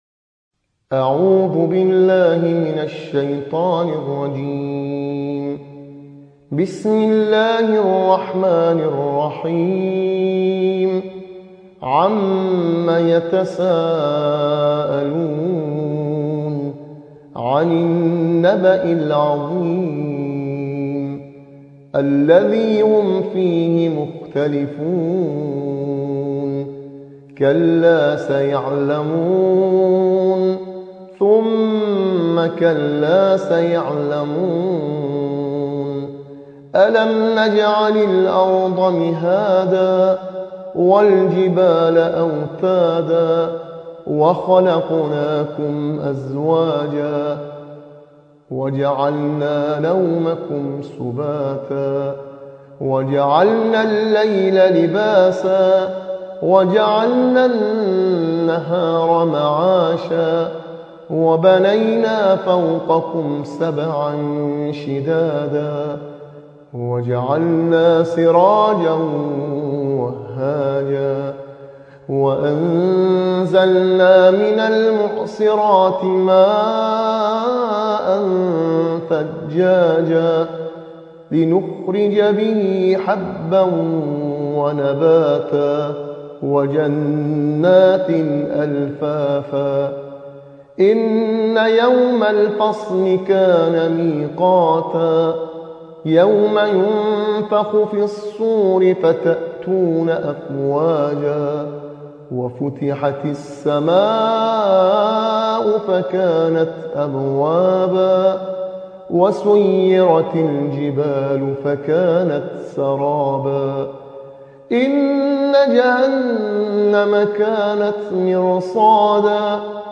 صوت | ترتیل‌خوانی جزء 29 و 30 قرآن